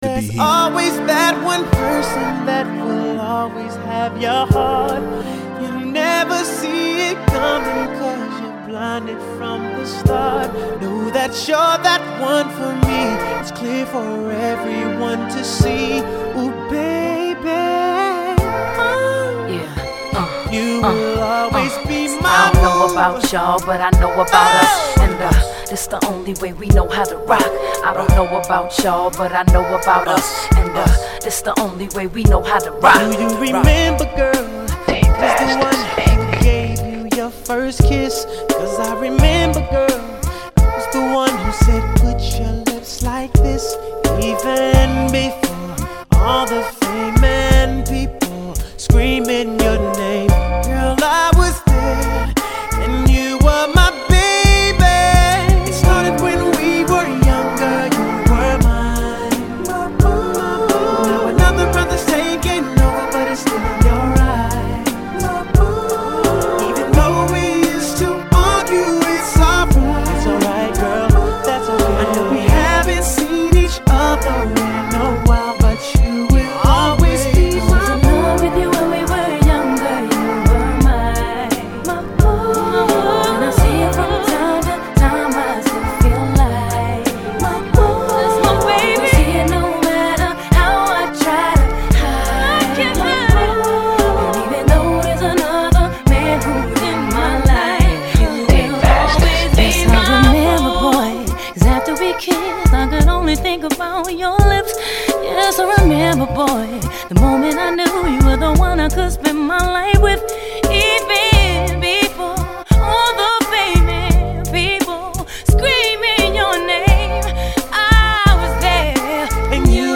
这首只要是懂Ｒ＆Ｂ的人都知道是Ｒ＆Ｂ小天王唱的